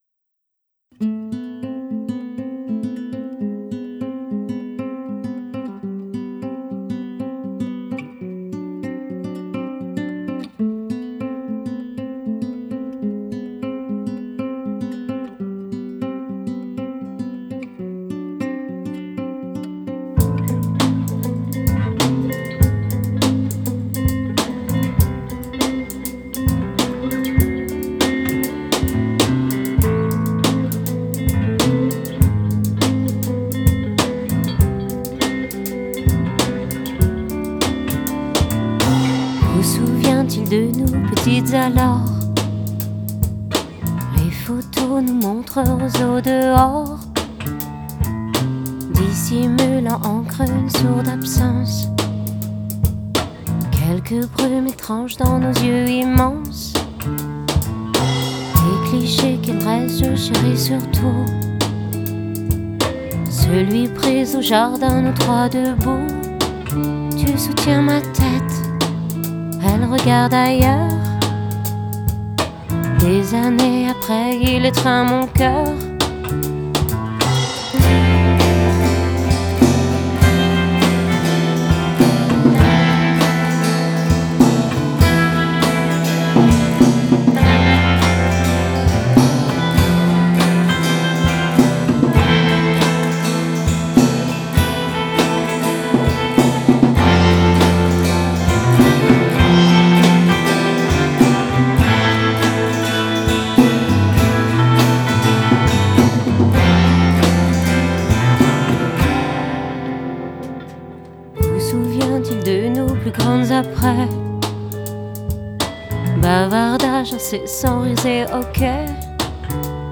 guitares/chant
batterie
basse
Enregistré rue de la Fidélité, Paris 10,